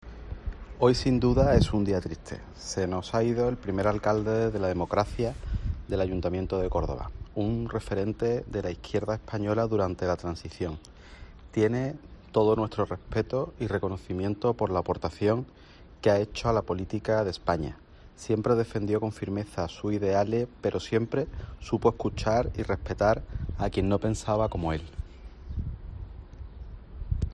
Antonio Repullo, delegado de la Junta de Andalucía en Córdoba